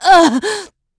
Isolet-Vox_Damage_04.wav